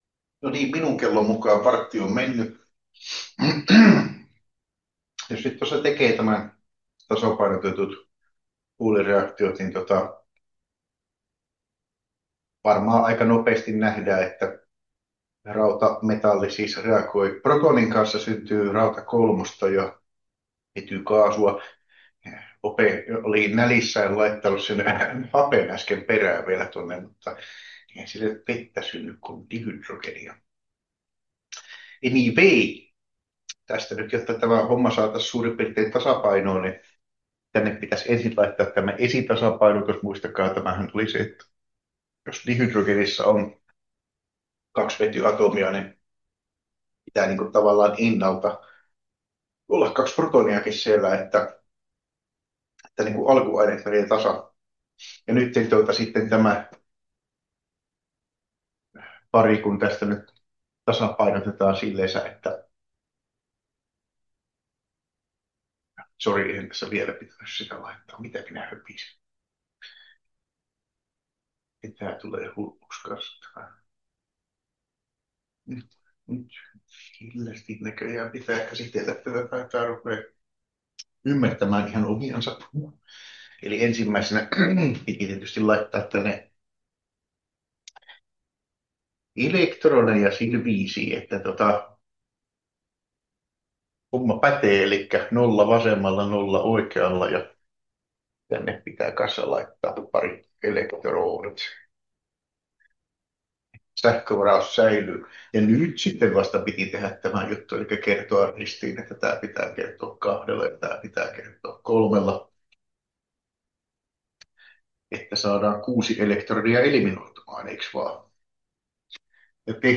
KEMP1111 luento 5 osa 2 — Moniviestin